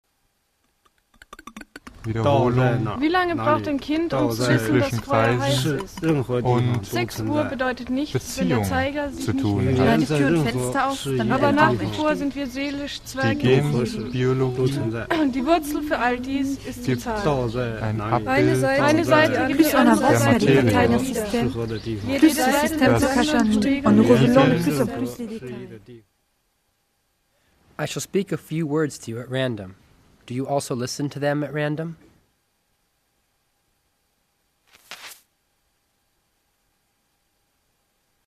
Klanginstallationen - Ausschnitte im mp3-Format